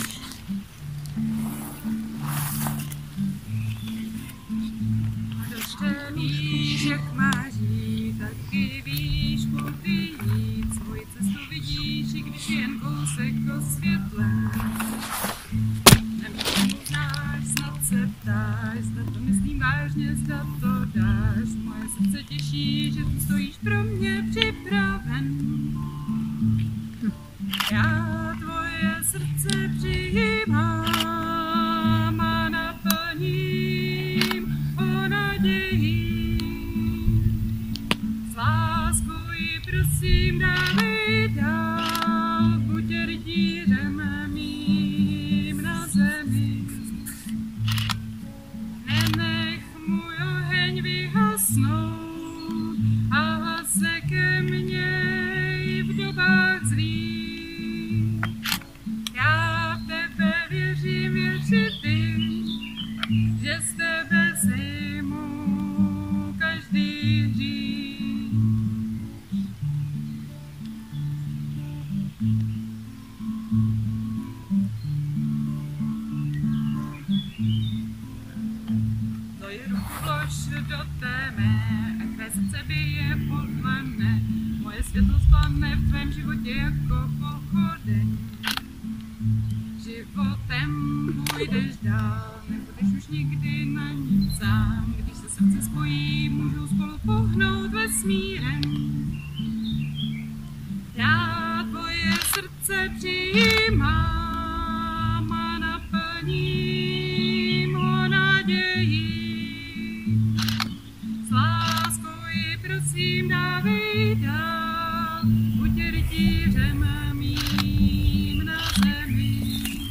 píseň ke křtu
křest-píseň.mp3